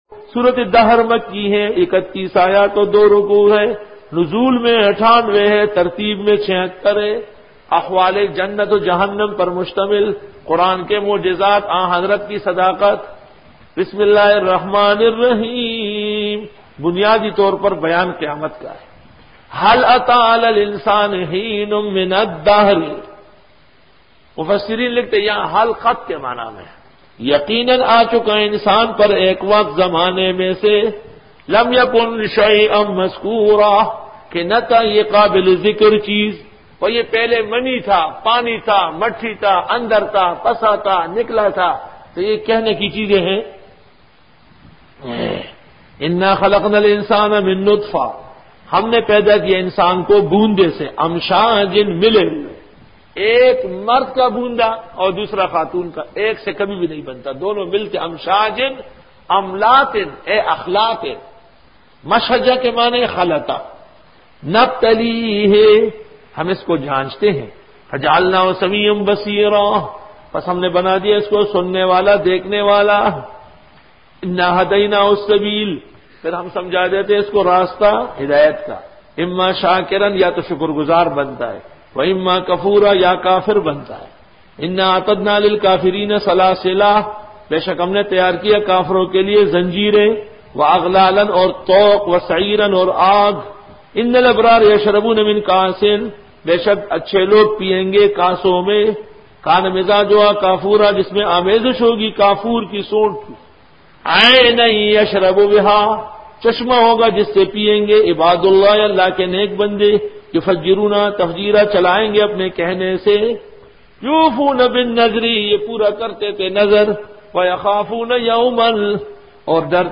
Dora-e-Tafseer 2000